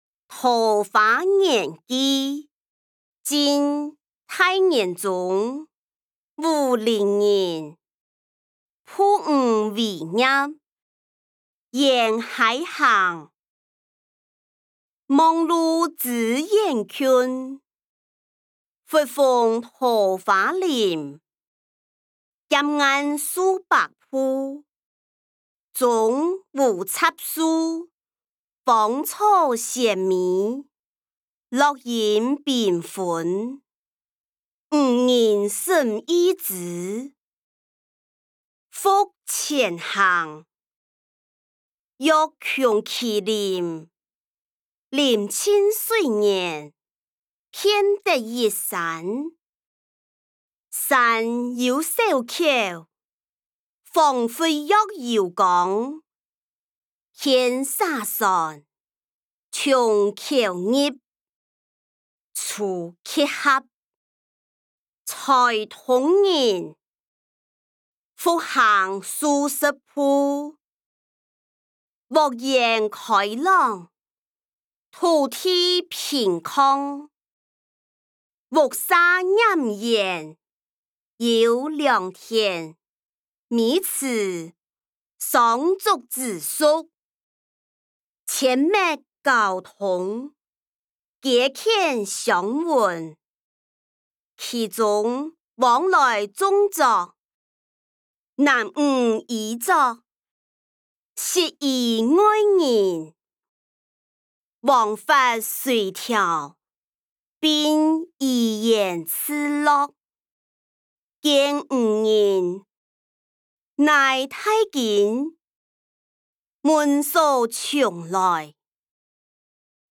歷代散文-桃花源記音檔(四縣腔)